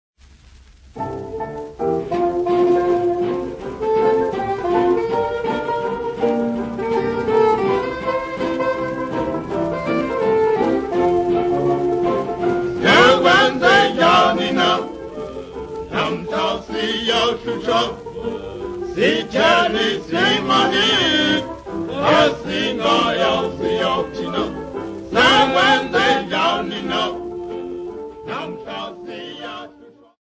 Popular music--Africa
Dance music
Dance music--Caribbean Area
sound recording-musical
Zulu male choir accompanied by a jazz band